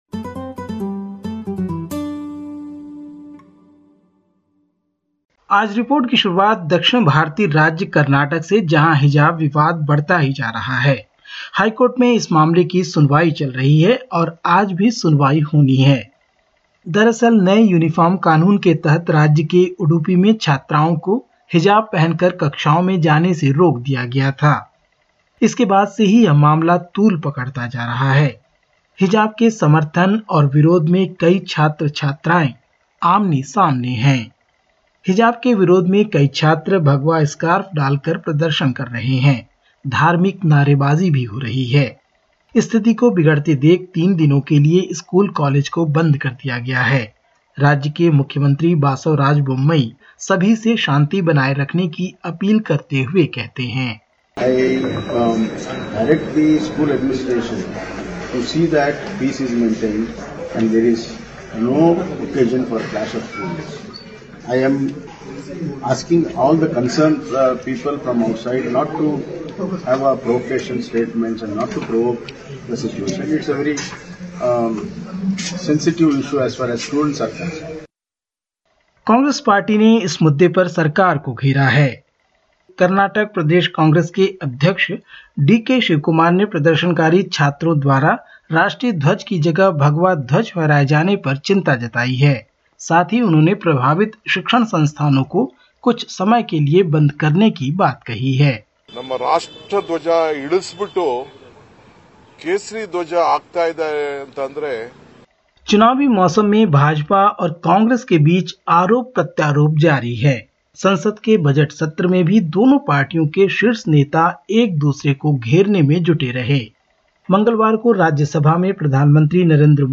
In this latest SBS Hindi report from India: Hijab row escalates in Karnataka causing the closure of high schools and colleges for three days; Rahul Gandhi responds to Prime Minister Narendra Modi’s attack on the Congress party; Uttar Pradesh set to vote for Legislative Assembly elections and more.